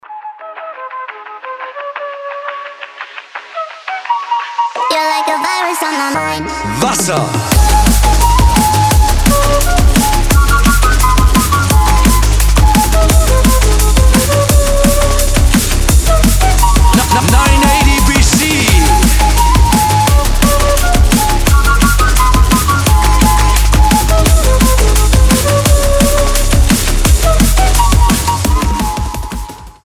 мелодичные
энергичные
быстрые
детский голос
драм энд бейс